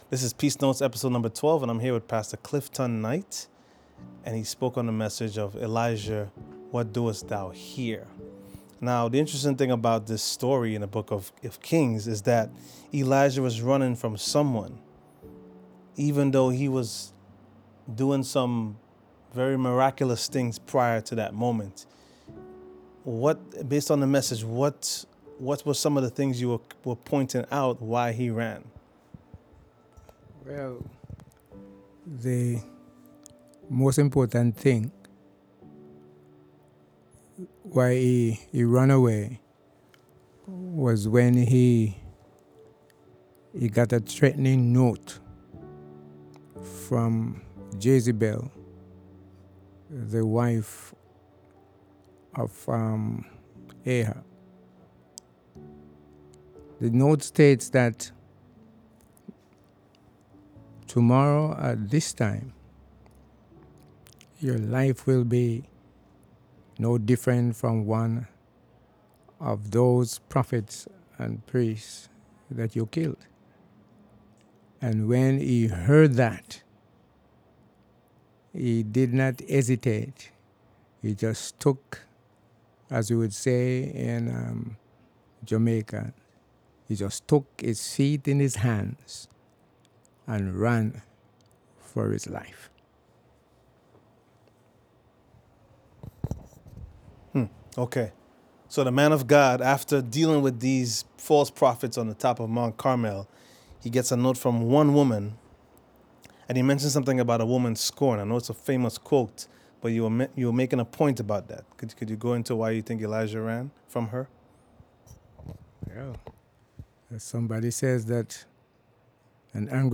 Sermon preached at Shalom SDA Church, Bronx.